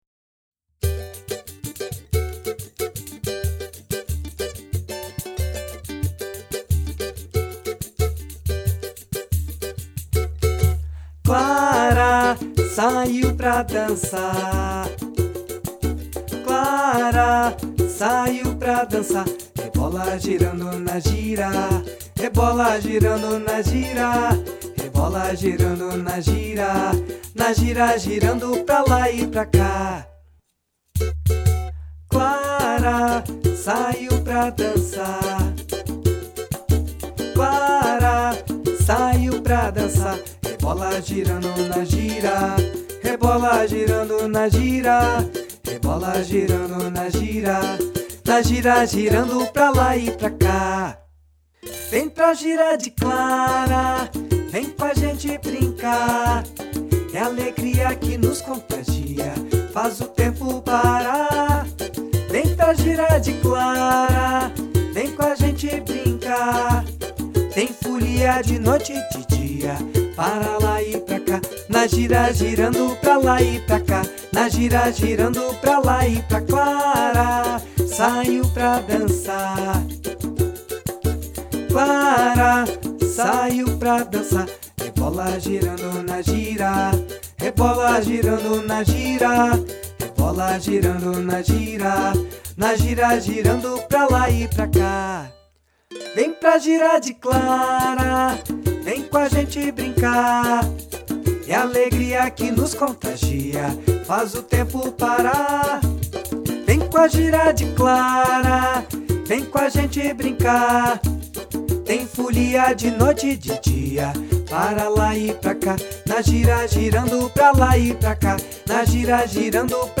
Violão
Percussão
Canção